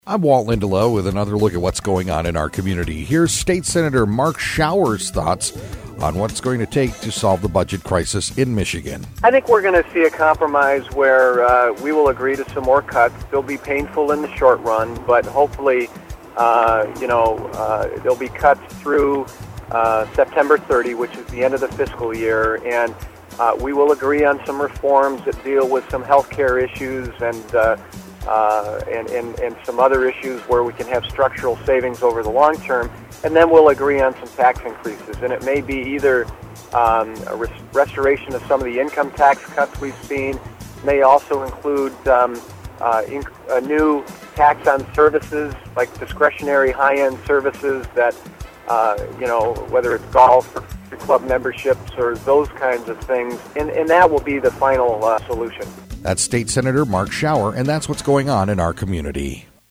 INTERVIEW: Mark Schauer, State Senator